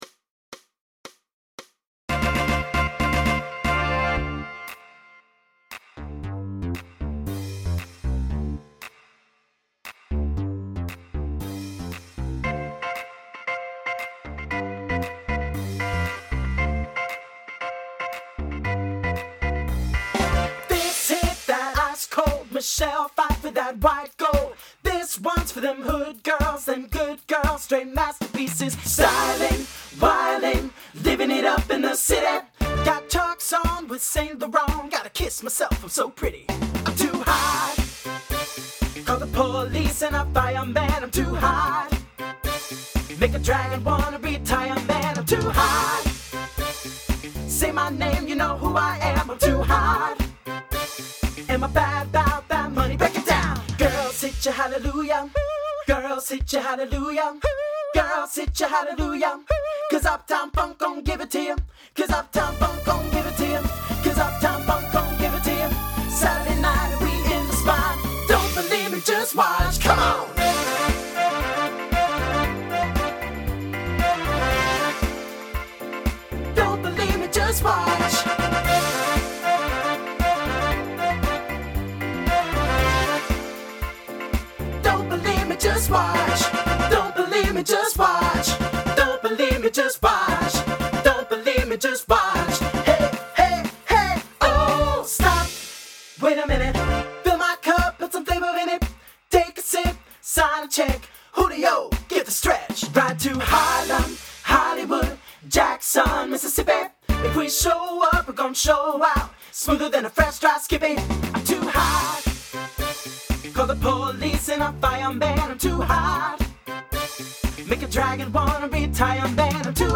Voicing SATB Instrumental combo Genre Pop/Dance